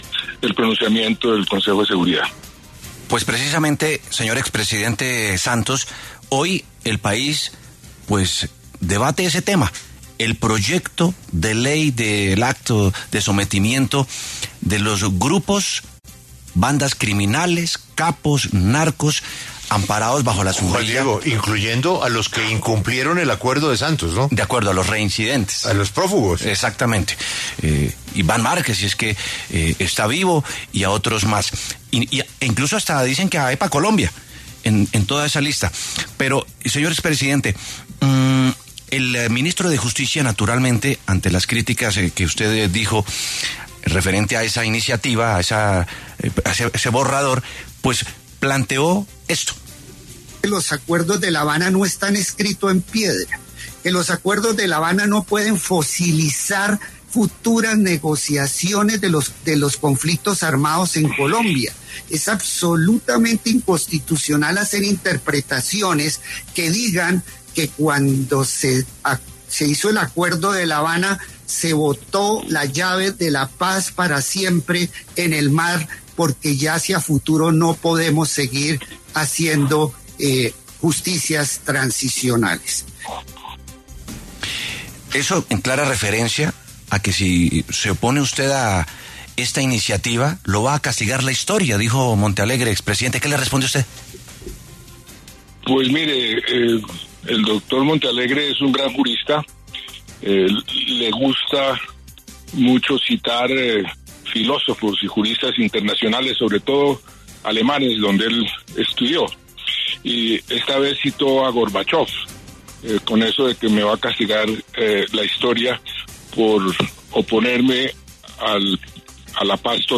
Este martes, 22 de julio, el expresidente de la República Juan Manuel Santos habló en los micrófonos de La W, con Julio Sánchez Cristo, sobre el proyecto de paz total que fue radicado este domingo, Día de la Independencia, por el ministro de Justicia, Eduardo Montealegre.